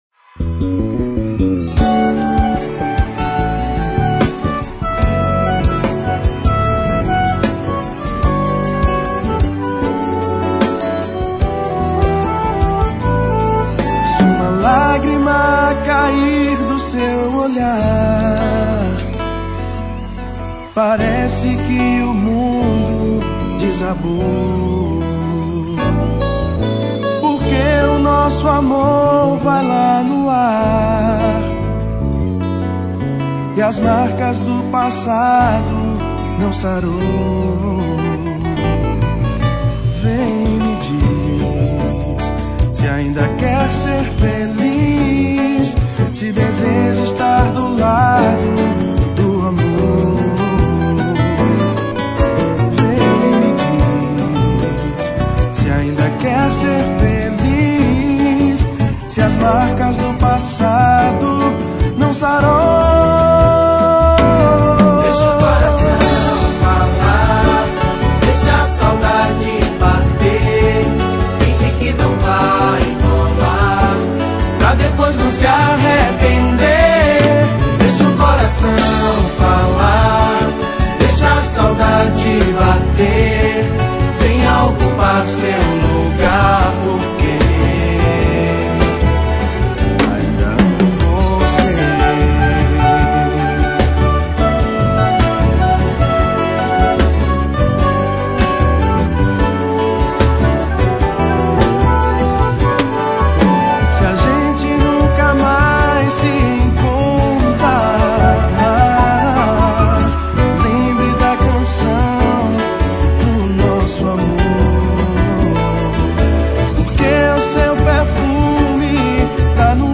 EstiloSamba